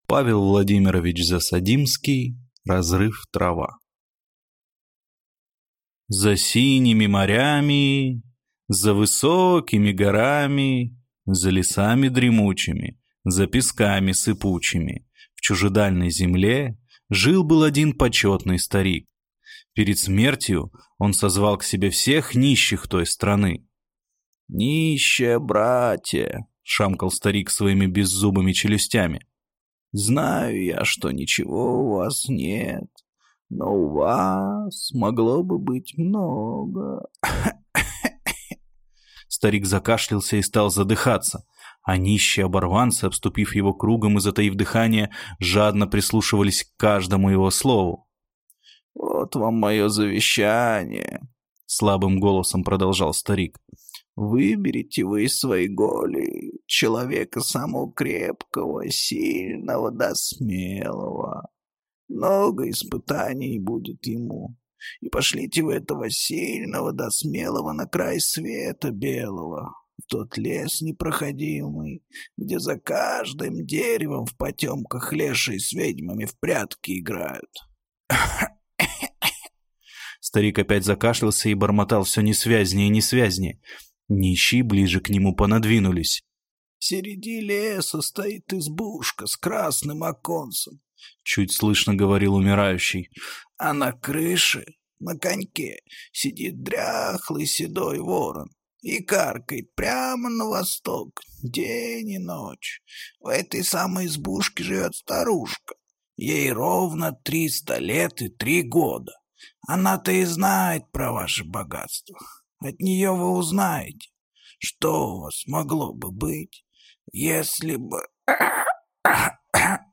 Аудиокнига Разрыв-трава | Библиотека аудиокниг